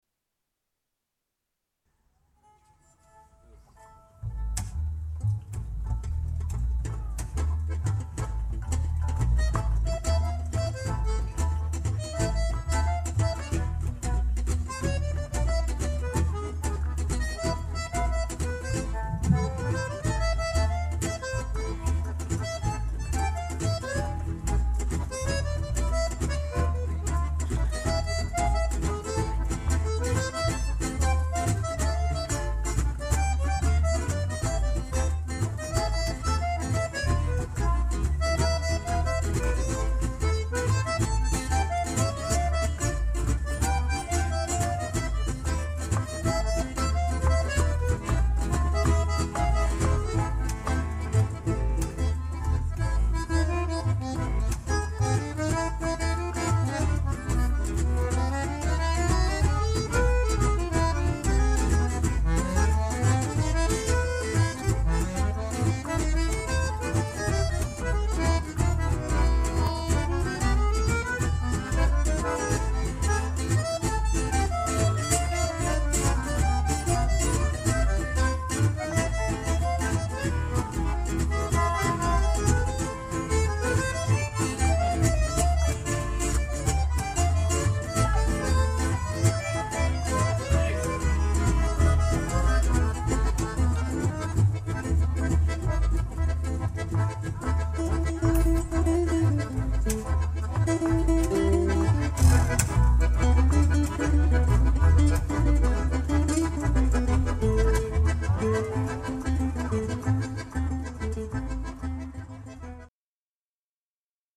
Swing Manouche, Musette, Balkan, Tango`s & Osteuropa ...